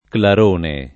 clarone [ klar 1 ne ]